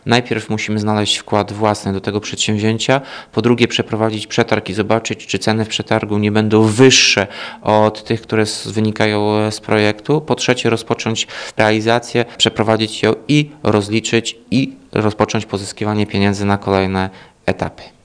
Mówi Tomasz Andrukiewicz, prezydent Ełku.
-Pozyskanie pieniędzy nie oznacza, że prace zaraz ruszą. Potrzebny jest jeszcze wkład własny – dodaje Andrukiewicz.